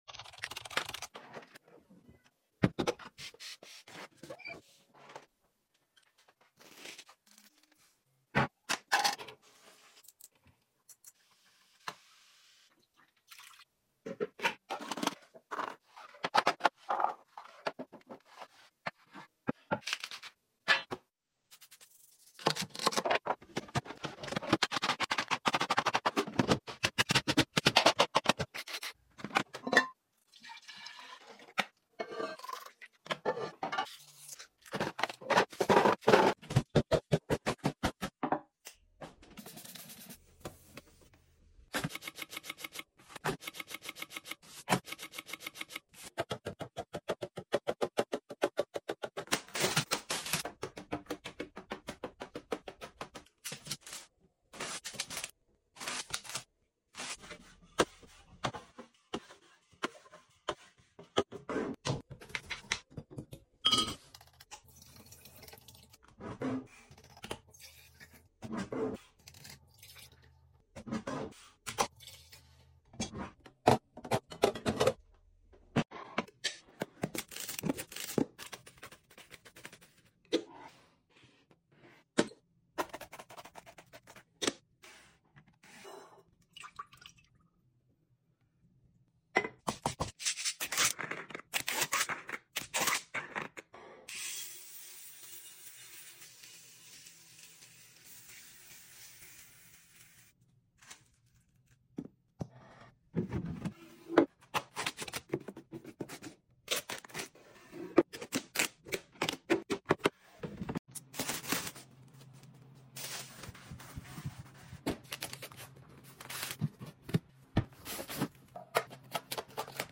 Asmr house restock, some random sound effects free download